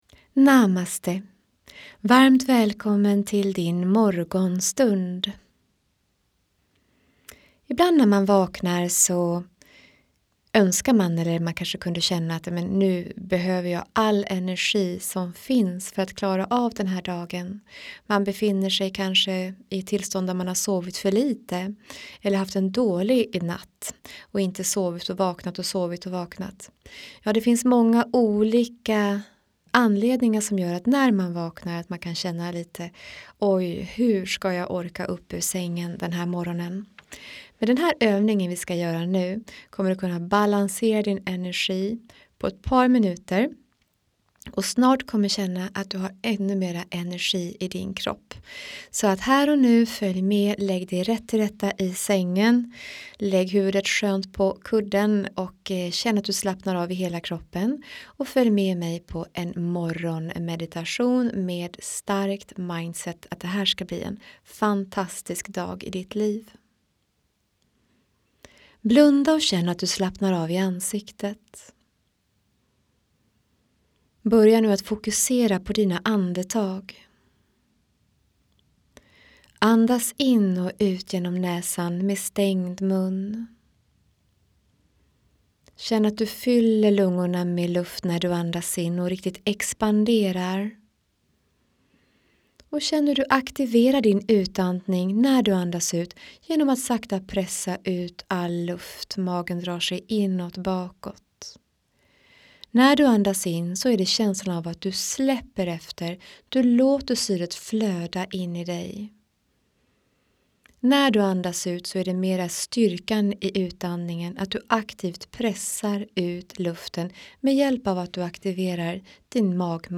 Här får du en mindset meditation för en bra och balanserad start på dagen.